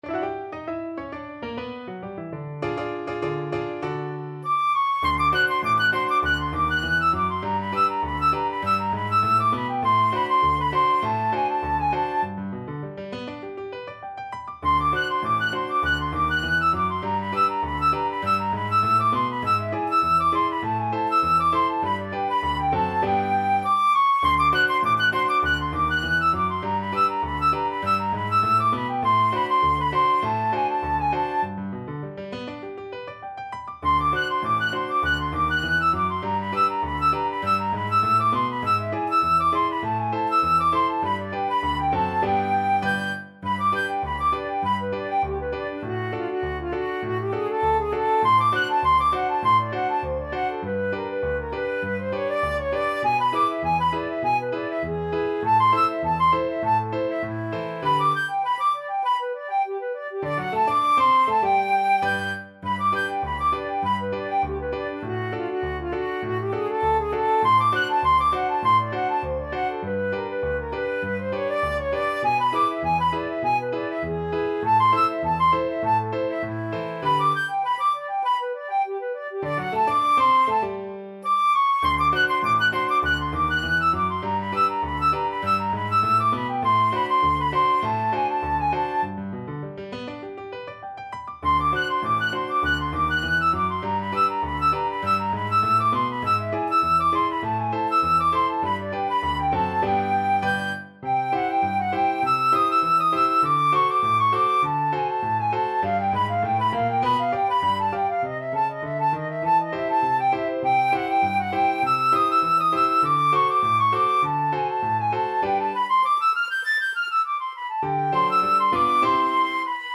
2/4 (View more 2/4 Music)
Moderato